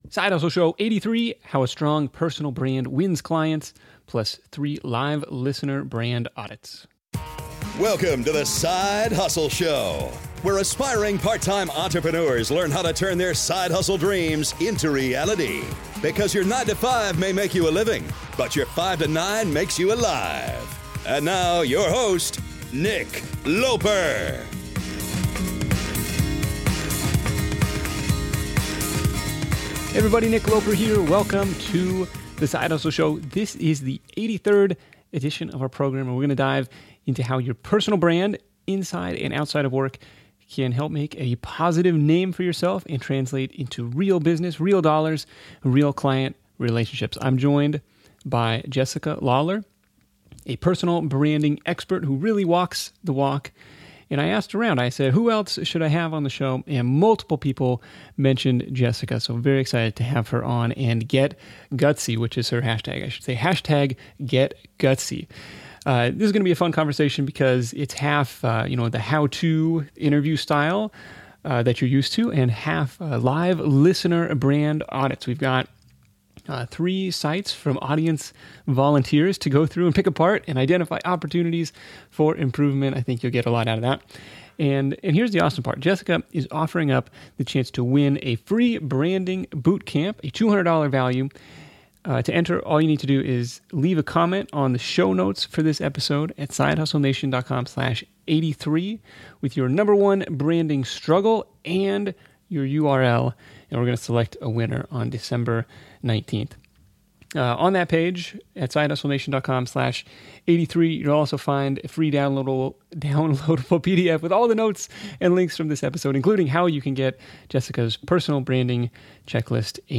During this call we discuss the merits of personal branding and why it's important even if you still have a day job. We also selected 3 audience victims/volunteers to have their websites and personal branding audited live on the air, good, bad, and ugly.